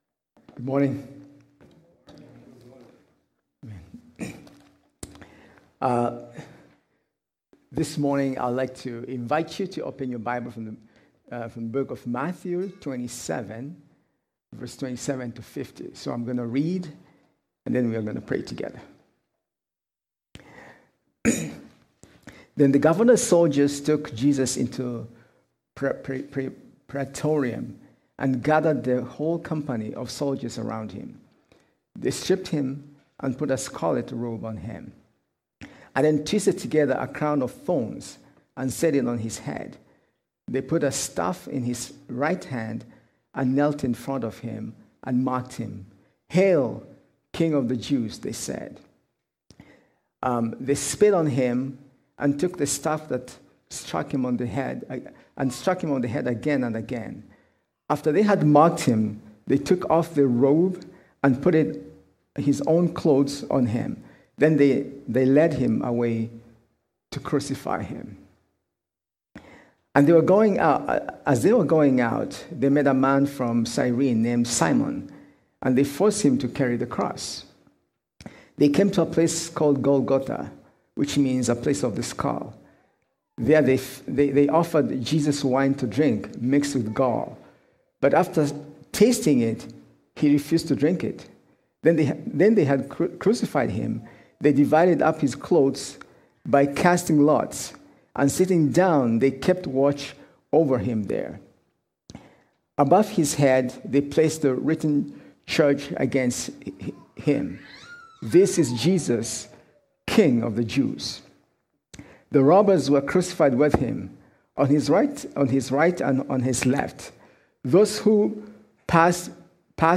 April-2-2021-Good-Friday-Service.mp3